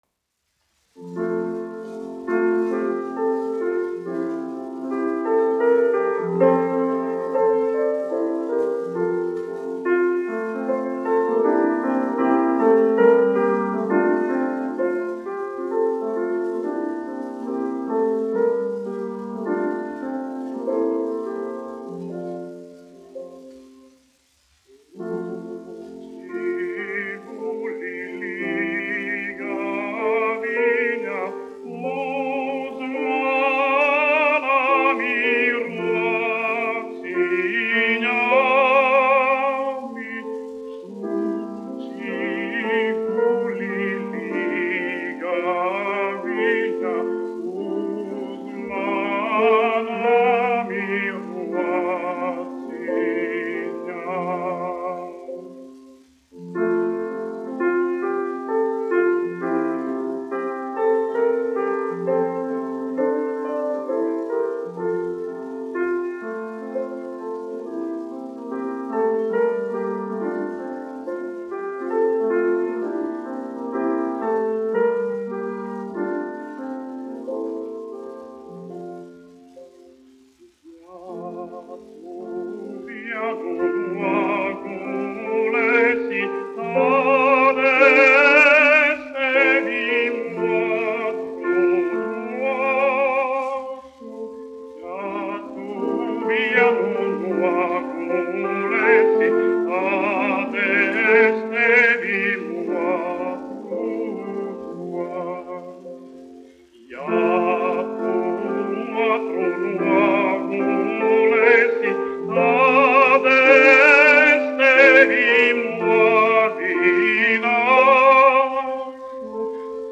1 skpl. : analogs, 78 apgr/min, mono ; 25 cm
Latviešu tautasdziesmas
Skaņuplate
Latvijas vēsturiskie šellaka skaņuplašu ieraksti (Kolekcija)